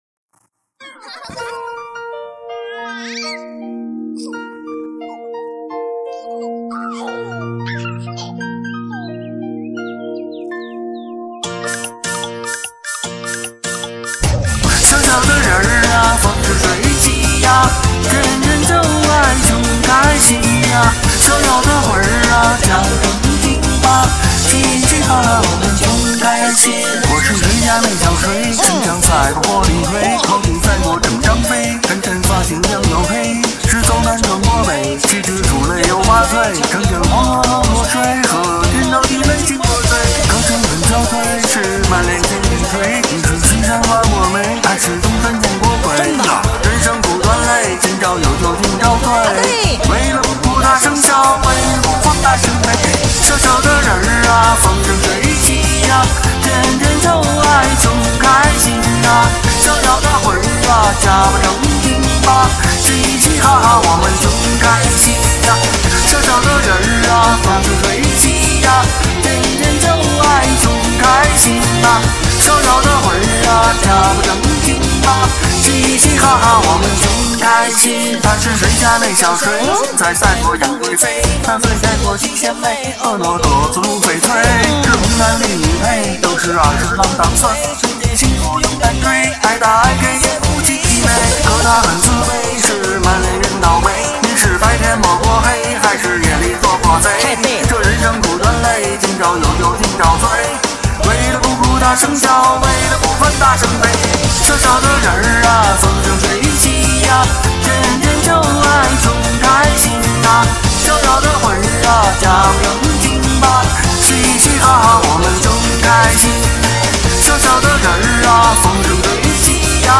把年味唱出来了。
热热闹闹欢天喜地